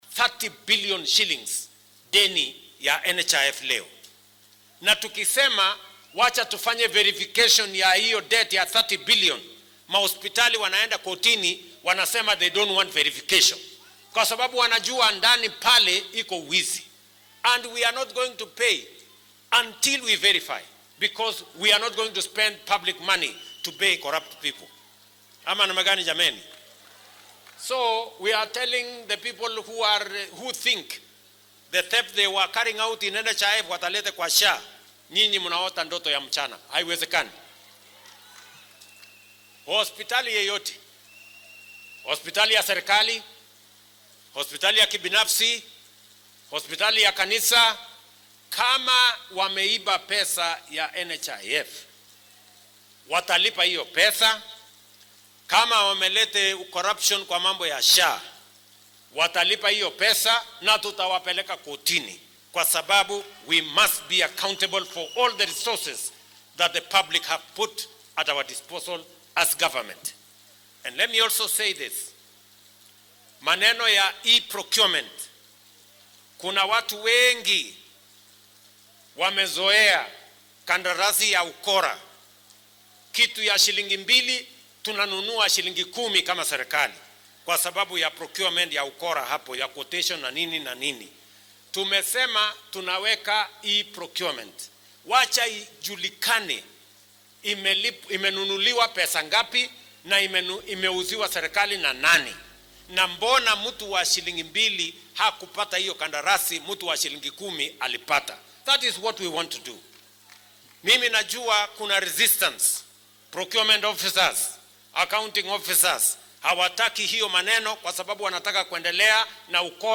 Madaxweynaha dalka William Ruto ayaa ku dhawaaqay dagaal dhan walba ah oo ka dhan ah dhaq-dhaqaaqa caafimaadka iyo wax soo iibsiga, isagoo uga digay saraakiisha musuqmaasuqa inay faraha kala baxaan ama ay cawaaqib xumo la kulmi doonaan. Mar uu arrimahani ka hadlayay madaxweynaha qaranka ayaa hadaladiisa waxaa ka mid ah.